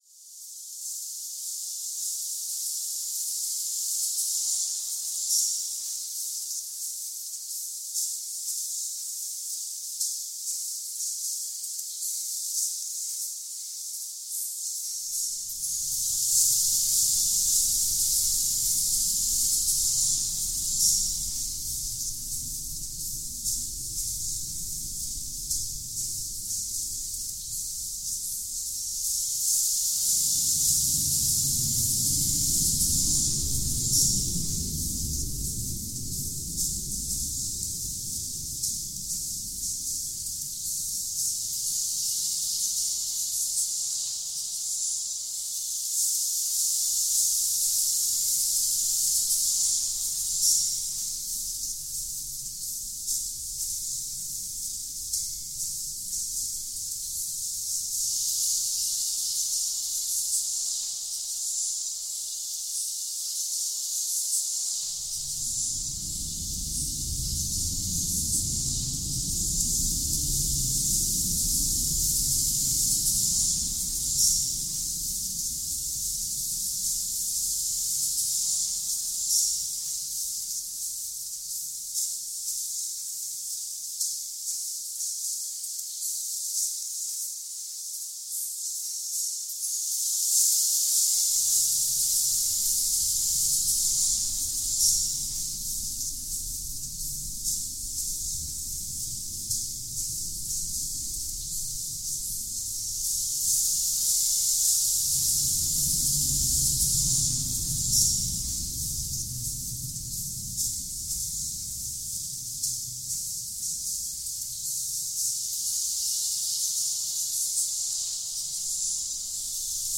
Ploshchad Revolyutsii station reimagined by Cities and Memory. Taking an extreme approach to EQing samples from the original recording, we've stripped the very top out to simulate the sounds of rain, and we've EQed right down to the lowest frequencies to represent the sounds of thunder. A light bit of reverb and tape effects to smear the edges of the sounds a little, and we have a rainstorm constructed entirely by EQing the original recording.